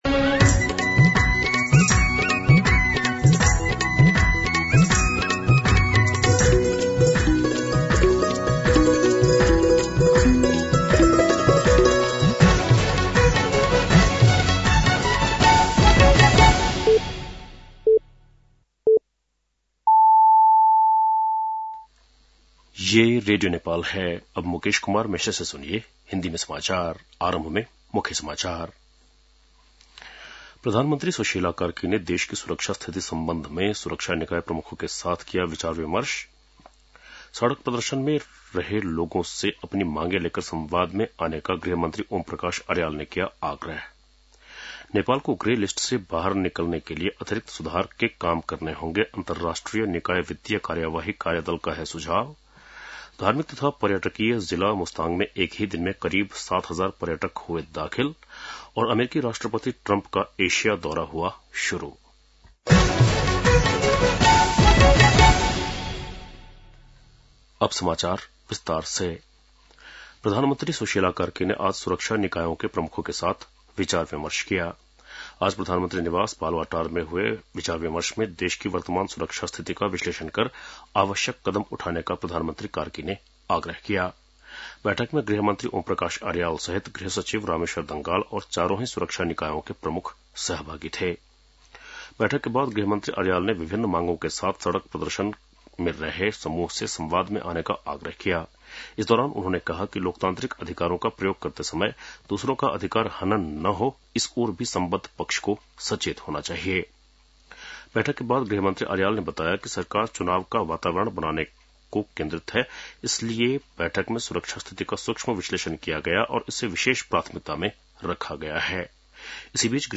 बेलुकी १० बजेको हिन्दी समाचार : ८ कार्तिक , २०८२